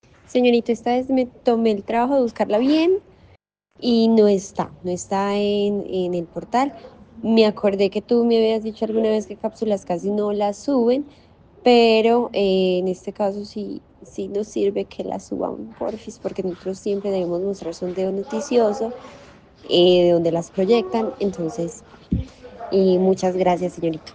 Declaraciones-de-la-secretaria-de-Inclusion-Social-y-Familia_-Sandra-Sanchez.mp3